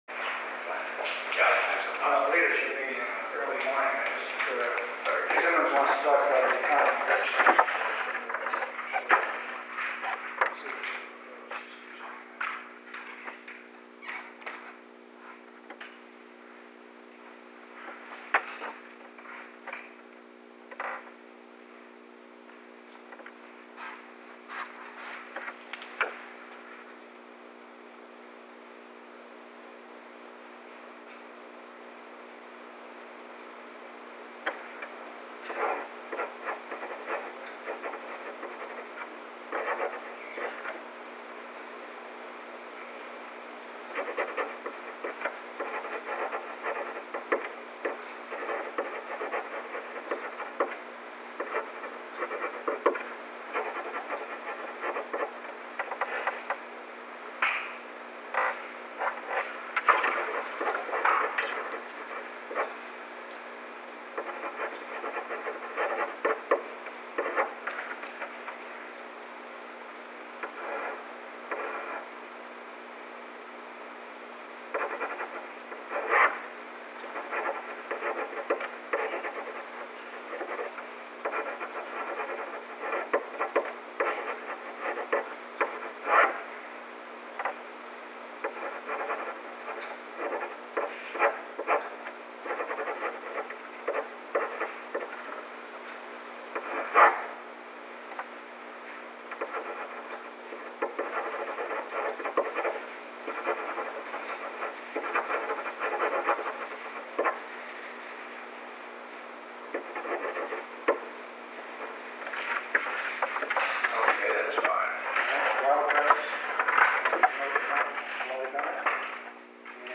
Secret White House Tapes
Conversation No. 763-10
Location: Oval Office
The President met with Alexander P. Butterfield.
[Signing of documents]